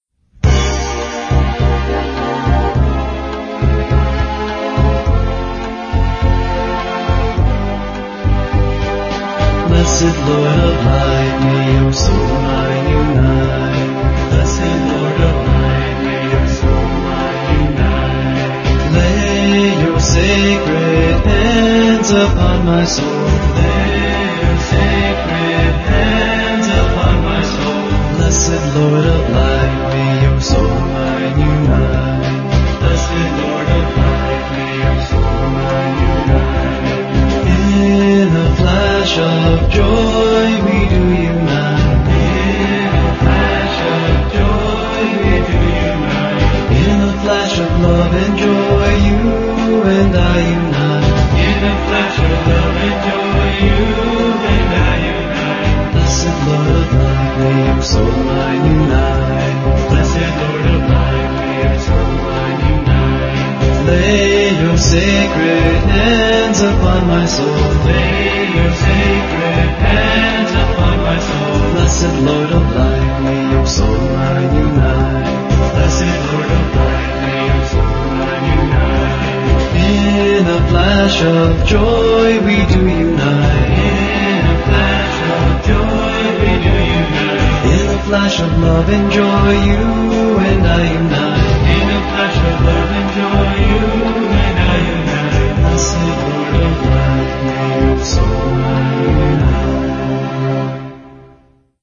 1. Devotional Songs
Major (Shankarabharanam / Bilawal)
8 Beat / Keherwa / Adi
Medium Fast
4 Pancham / F
1 Pancham / C
Lowest Note: d2 / A (lower octave)
Highest Note: D2 / A